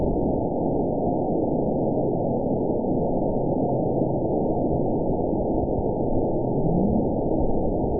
event 916803 date 02/02/23 time 18:24:17 GMT (2 years, 3 months ago) score 8.63 location TSS-AB04 detected by nrw target species NRW annotations +NRW Spectrogram: Frequency (kHz) vs. Time (s) audio not available .wav